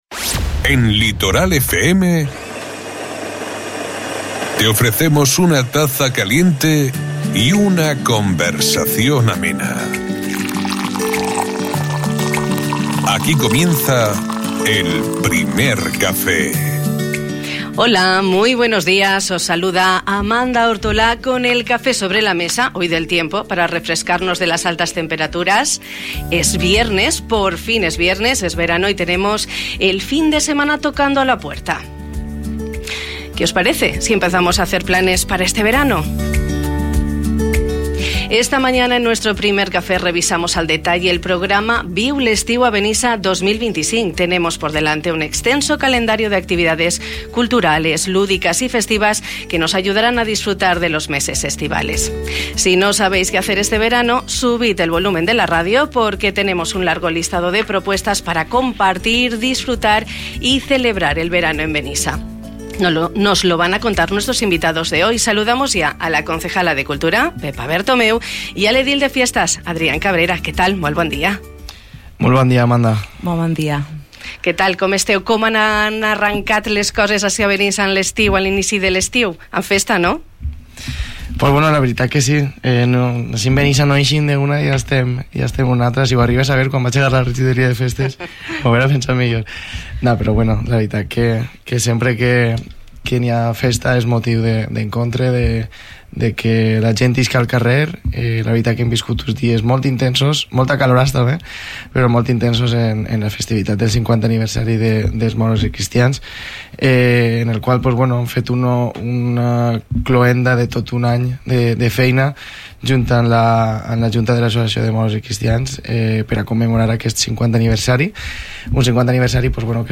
Esta mañana en el Primer Café de Radio Litoral hemos revisado al detalle el programa “Viu l’Estiu a Benissa 2025”.
Una variada programación con propuestas para compartir, disfrutar y celebrar el verano en Benissa, como nos han contado la concejala de Cultura, Pepa Bertomeu, y el edil de Fiestas, Adrián Cabrera.
Adrián Cabrera, concejal de Fiestes , y Pepa Bertomeu, concejala de Cultura.